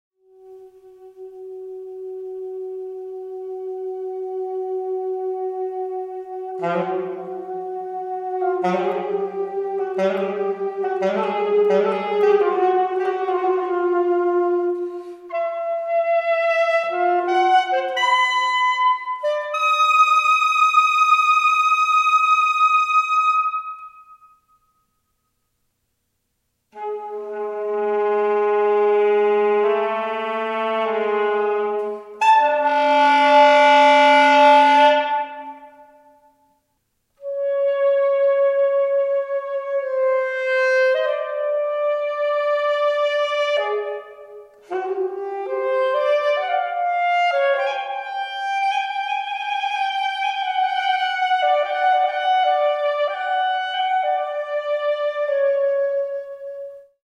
Alto Saxophone: